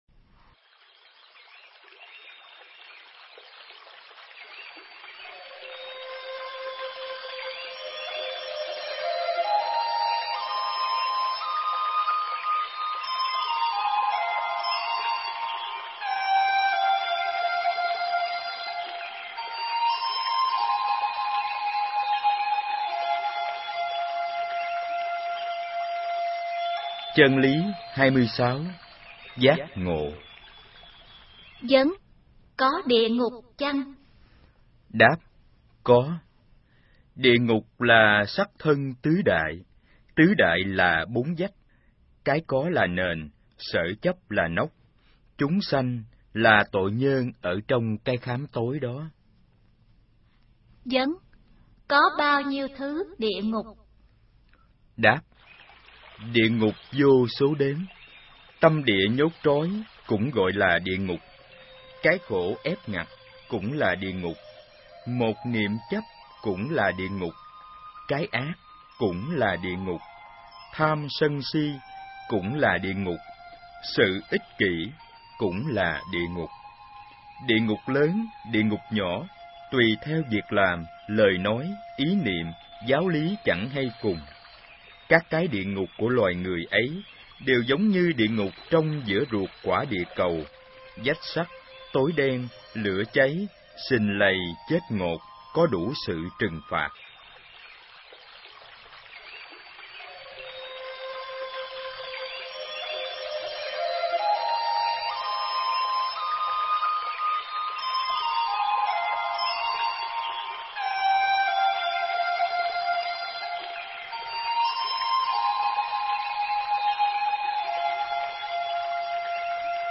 Nghe sách nói chương 26. Giác Ngộ